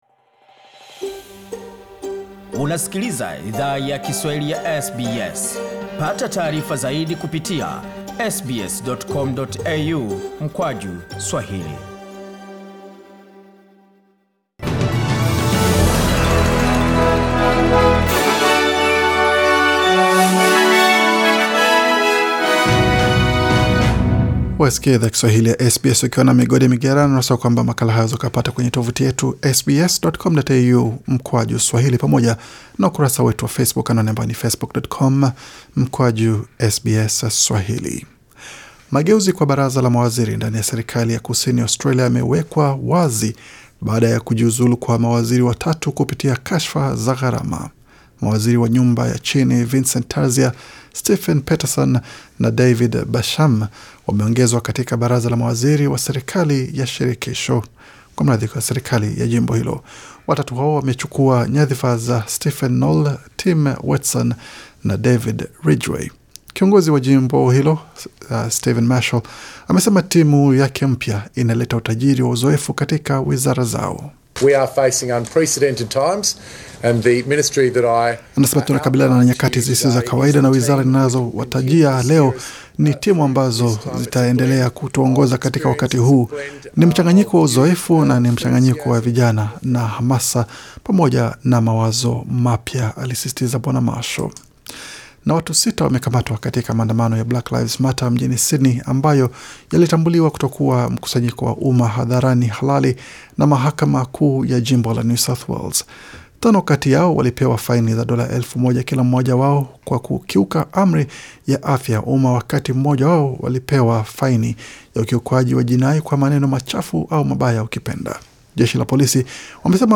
Taarifa ya habari 28 Julai 2020